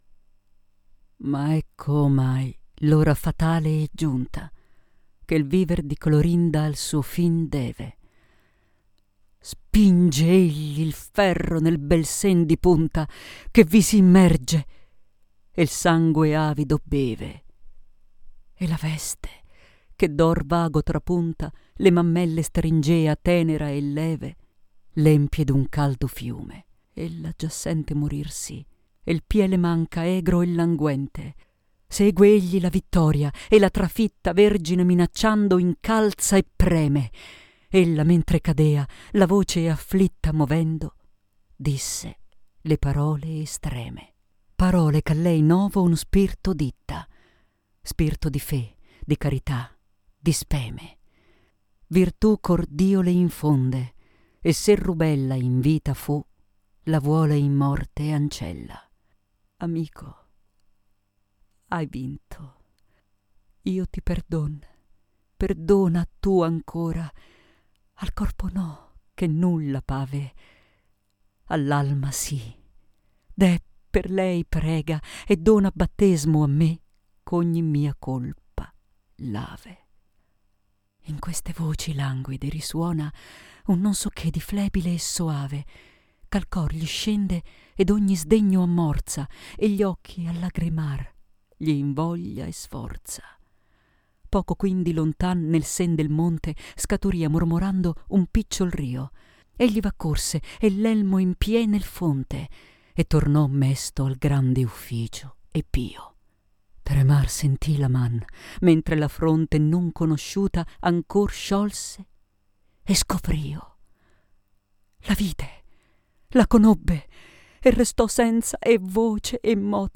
READINGS